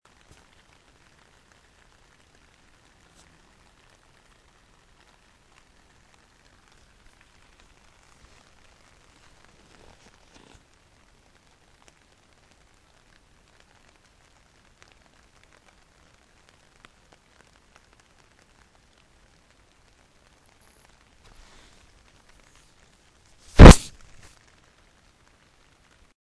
Rain on Tent Boo
46573-rain-on-tent-boo.mp3